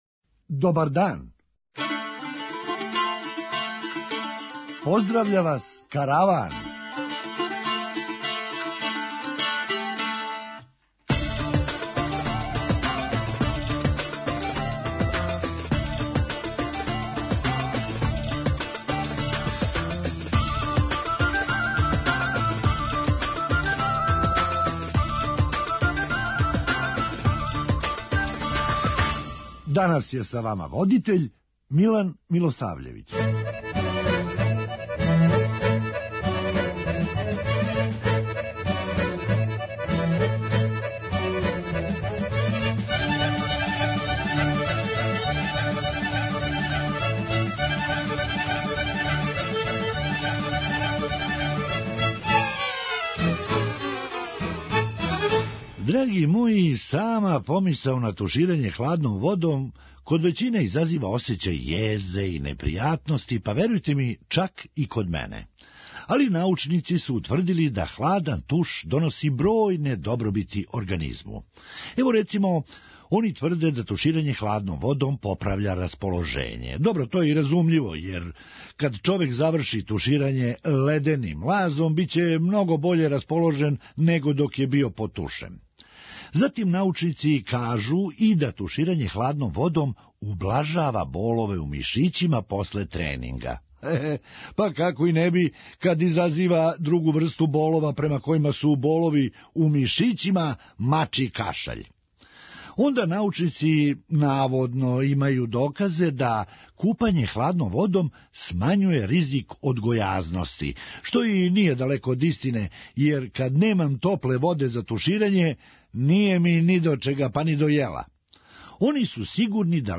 Хумористичка емисија
Баш нас интересује који гост није појео то парче торте него га је понео кући!? преузми : 9.00 MB Караван Autor: Забавна редакција Радио Бeограда 1 Караван се креће ка својој дестинацији већ више од 50 година, увек добро натоварен актуелним хумором и изворним народним песмама.